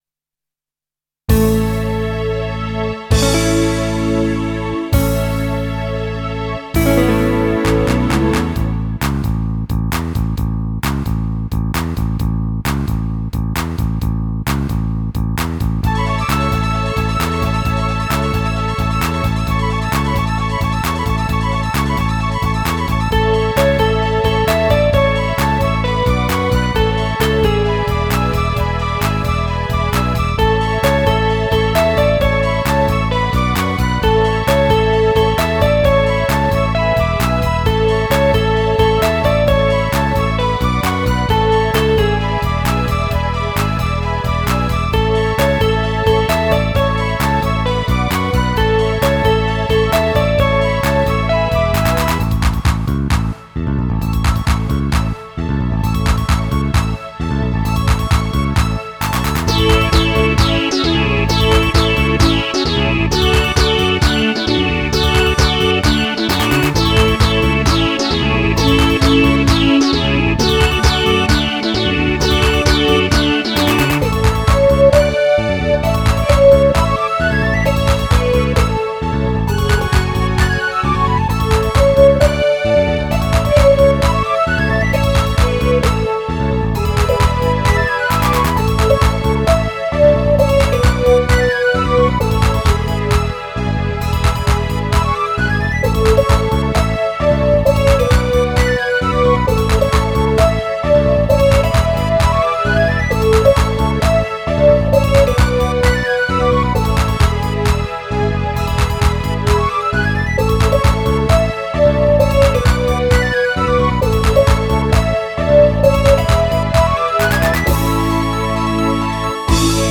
一部コンピュータぽいところあり。SC-88ProのHumanizerを使用。